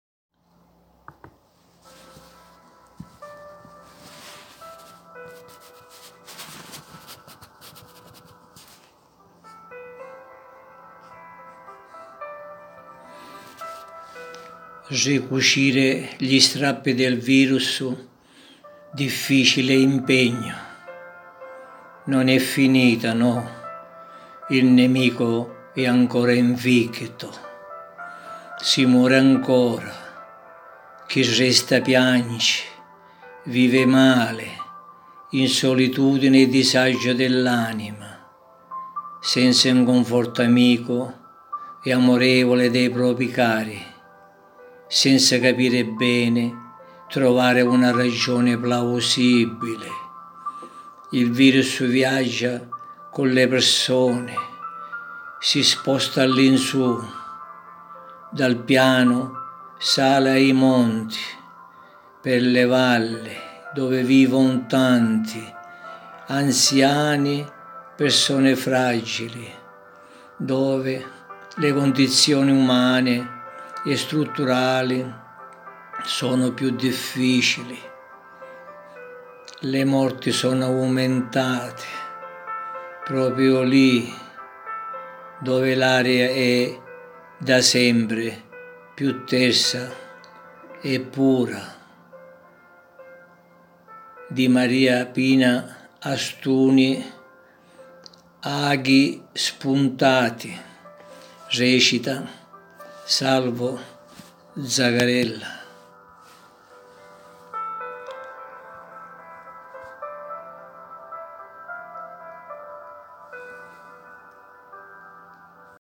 interpreta la poesia